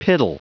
Prononciation du mot piddle en anglais (fichier audio)
Prononciation du mot : piddle